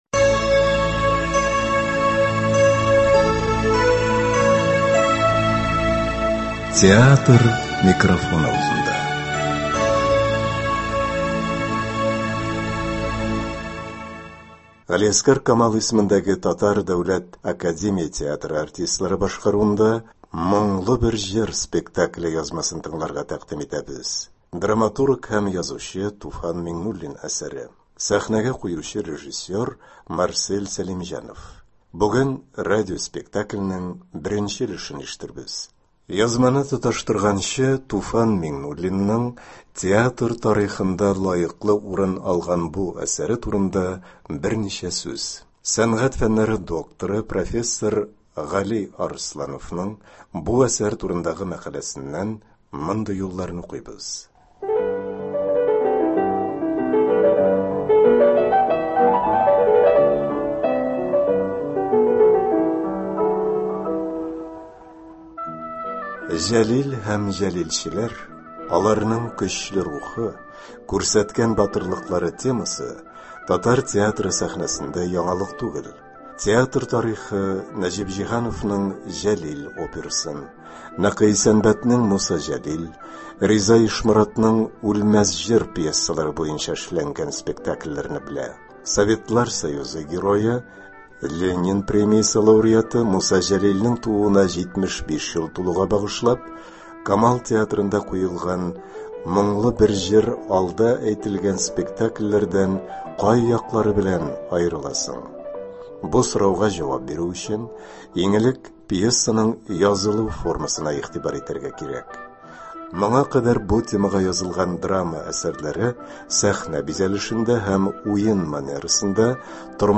Г.Камал исемендәге ТДАТ спектакле. 1 нче өлеш.
Г.Камал исемендәге Татар Дәүләт академия театрының “Моңлы бер җыр” спектакле язмасын тыңларга тәкъдим итәбез. Драматург һәм язучы Туфан Миңнуллин әсәре.